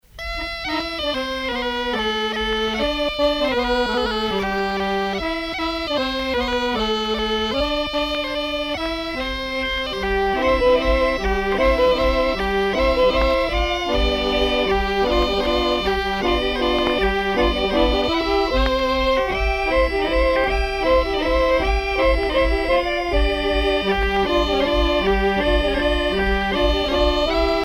danse : gâtinelle
Pièce musicale éditée